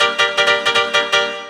hous-tec / 160bpm / piano